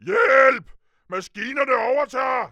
Ohno2.wav